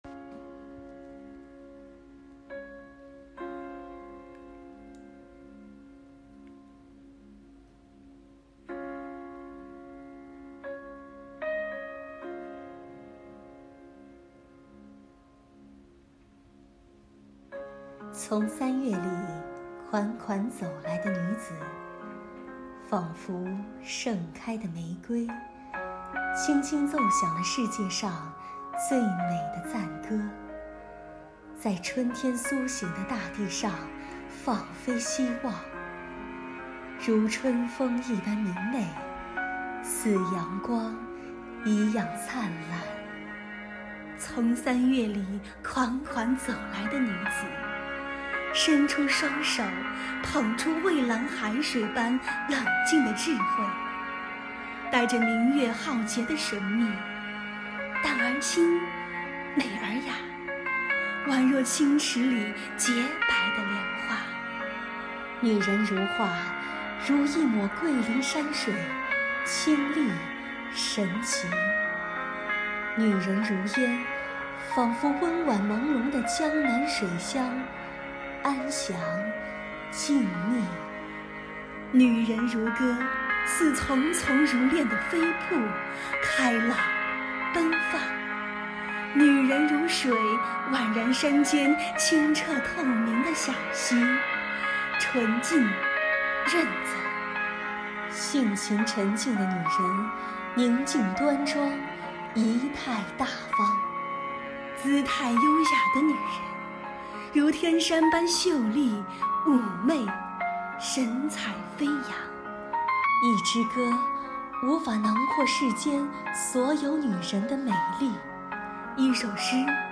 在第110个“三八”国际劳动妇女节之际，四渡赤水纪念馆以美文、美诵方式祝福社会各界的妇女同胞们节日快乐！